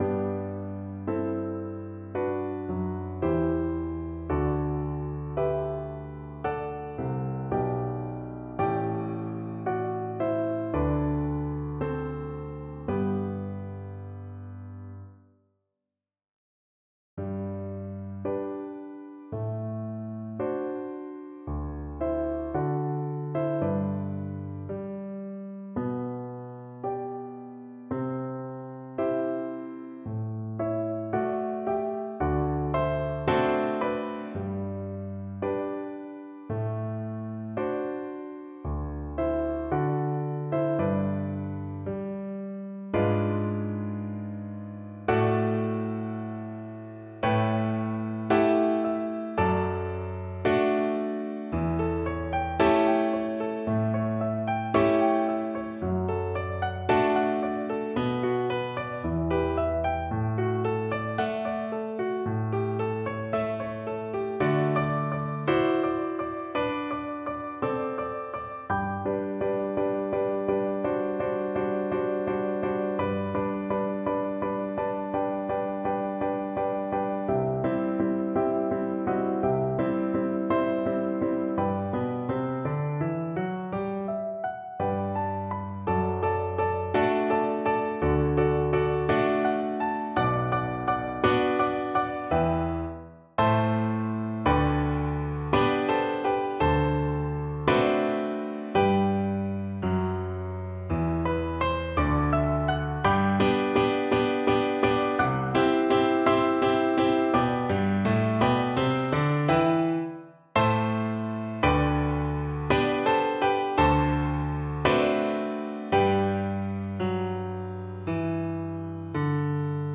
4/4 (View more 4/4 Music)
= 86 Andante moderato (View more music marked Andante Moderato)
G major (Sounding Pitch) (View more G major Music for Cello )
Cello  (View more Intermediate Cello Music)
Classical (View more Classical Cello Music)